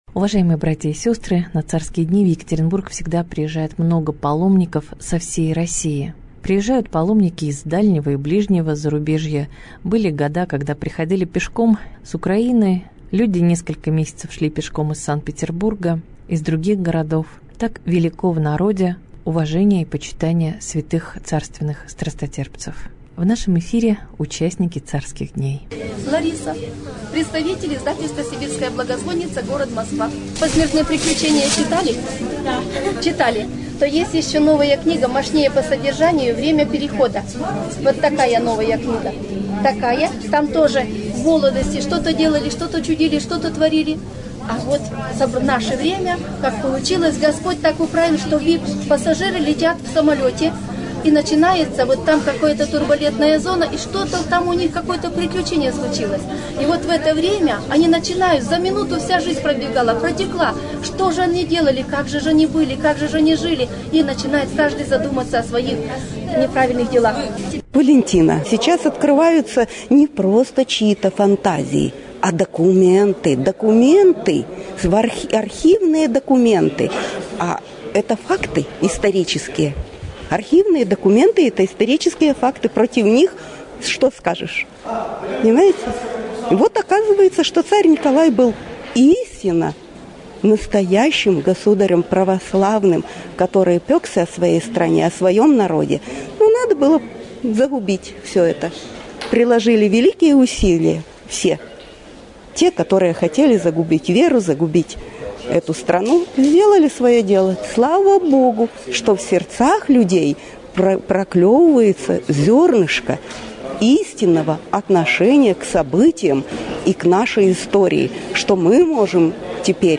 Репортаж дня